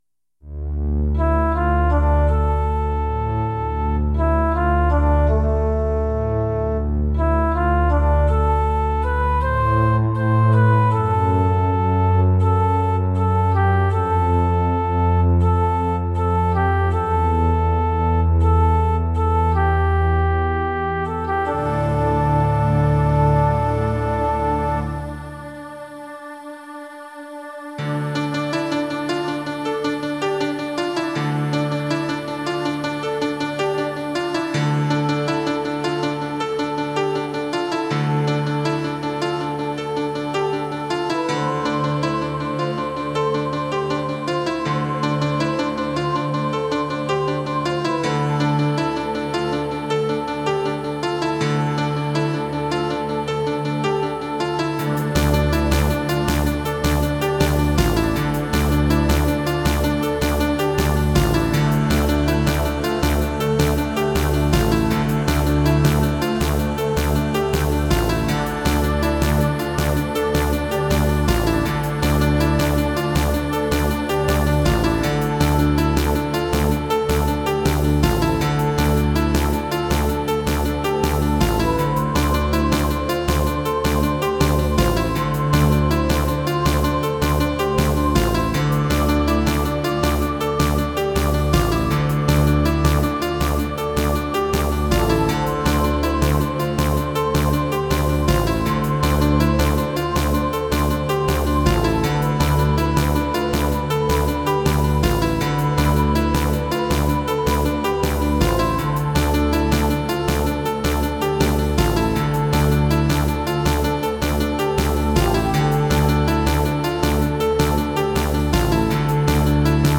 semi-techno